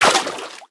main Divergent / mods / Soundscape Overhaul / gamedata / sounds / material / human / step / water01gr.ogg 8.1 KiB (Stored with Git LFS) Raw Permalink History
water01gr.ogg